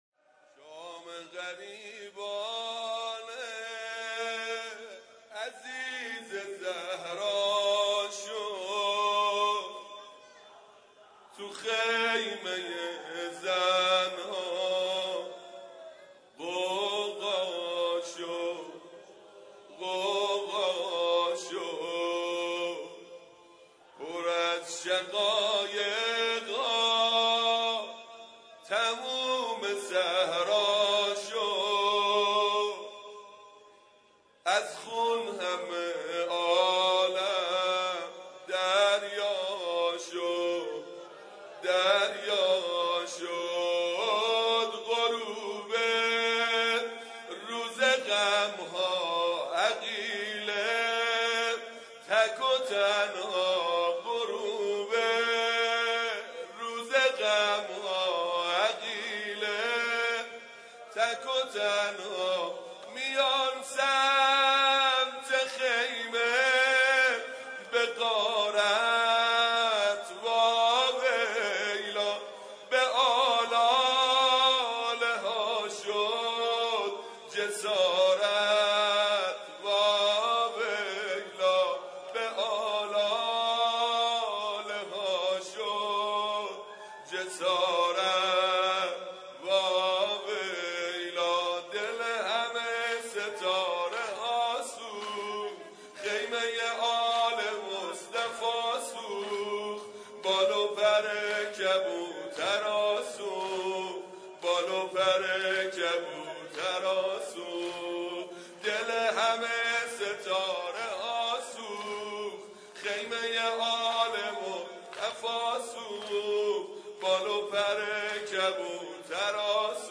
بخش اول: مناجات
بخش سوم:مراسم احیا شب قدر